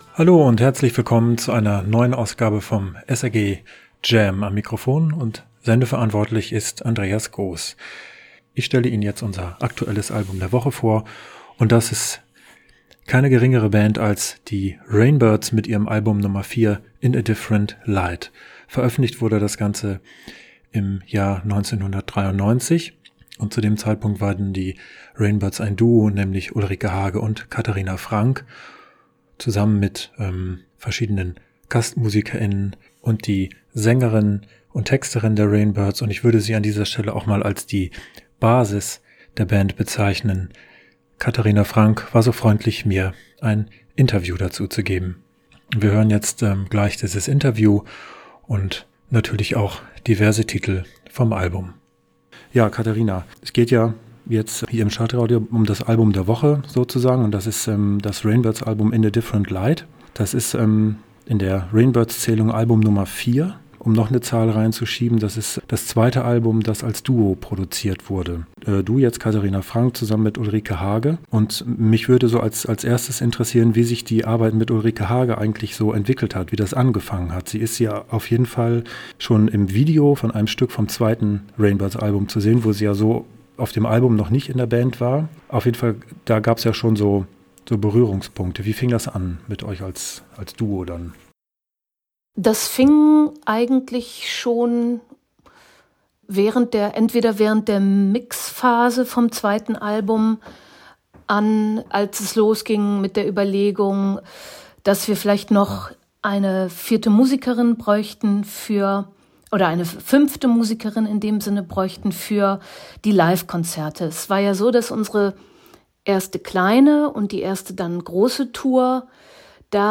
In A Different Light: Interview